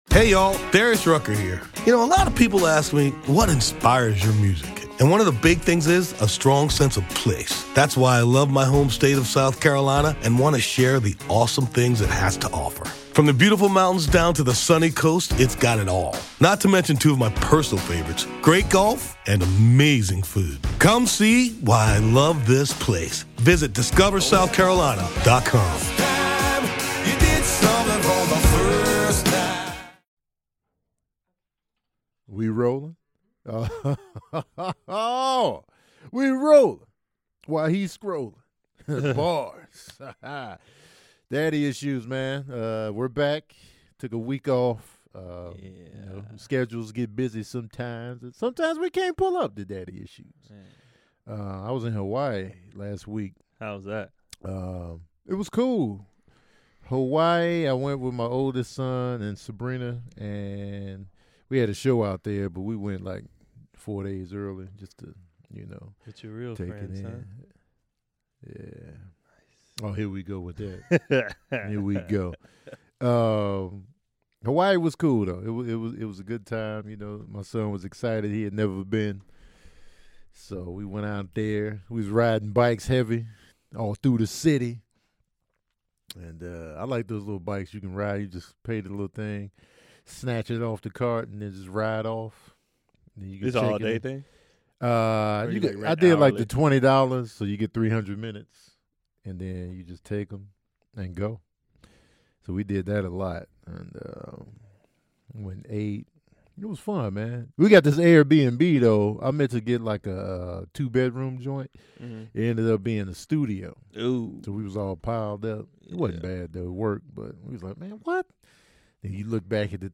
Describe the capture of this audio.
After a week off, we're back in the studio talking about traveling for work, paying these got damn bills and keeping a clean house.